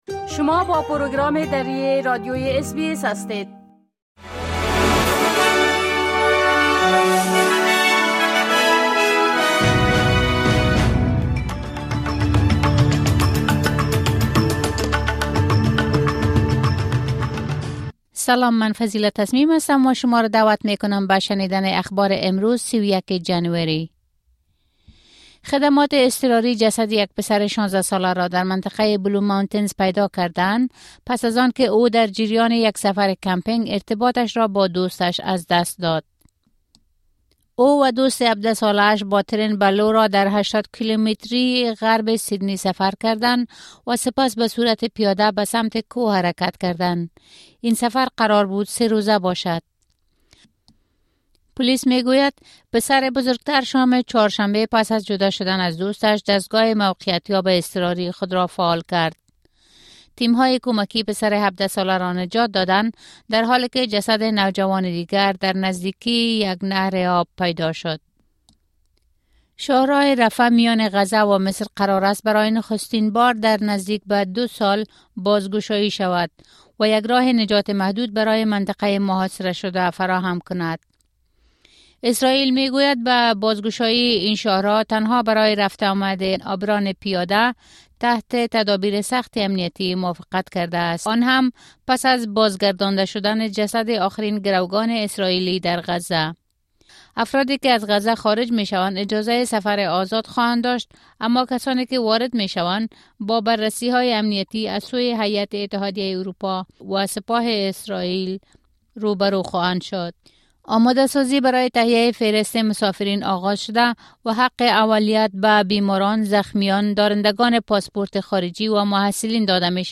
خلاصه‌ای مهم‌ترين خبرهای روز | ۳۰ جنوری